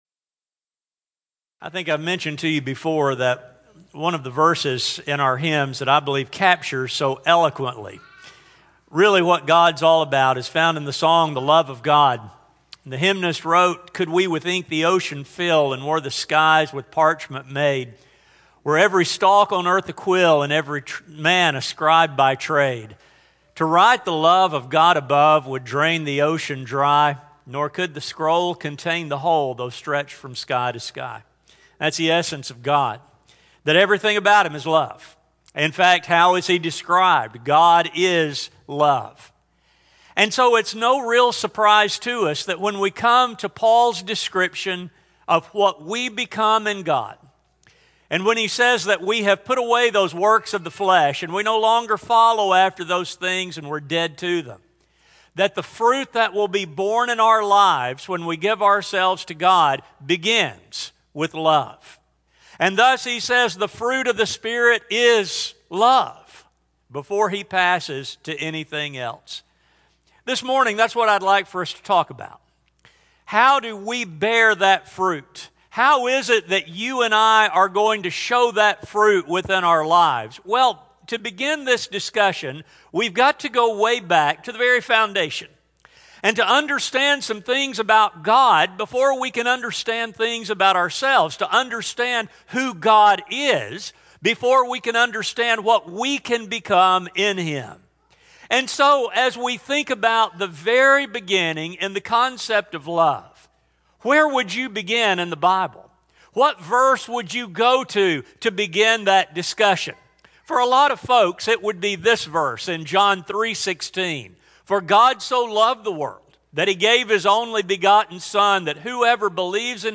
In Step With the Spirit in an Out of Step World Service: Sun AM Type: Sermon Speaker